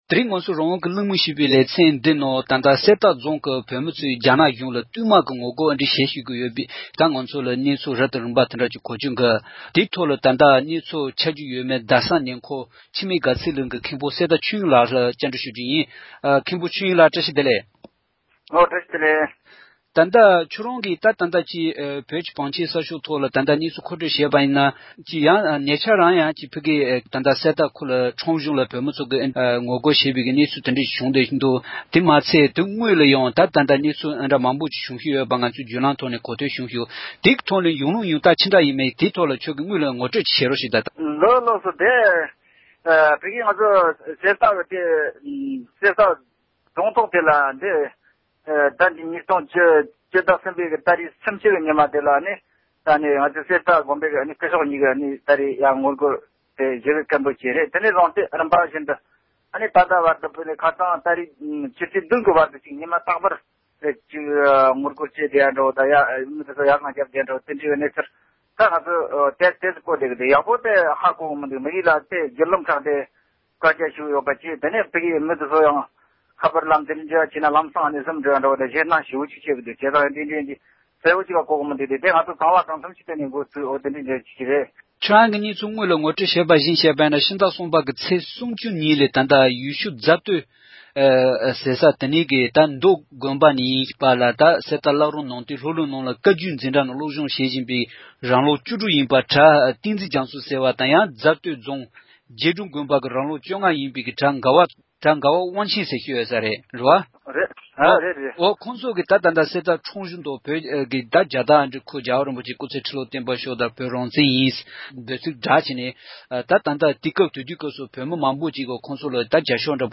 བཀའ་འདྲི་ཞུས་པར